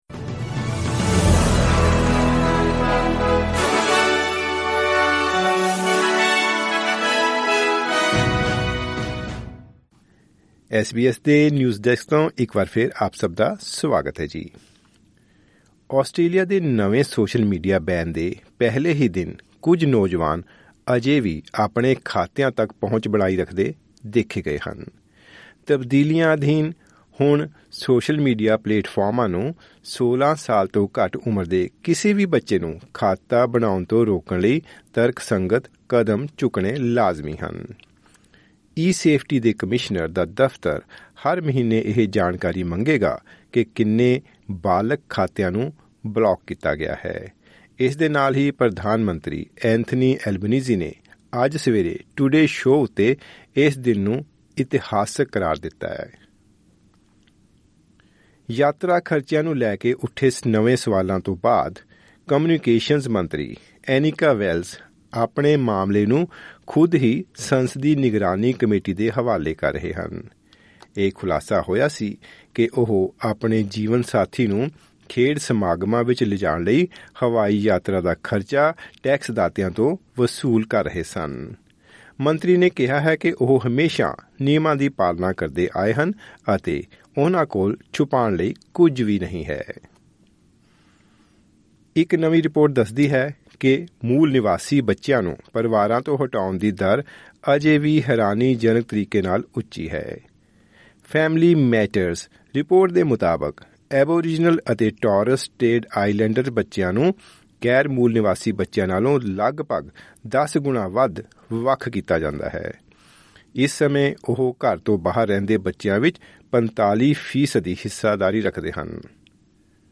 ਖ਼ਬਰਨਾਮਾ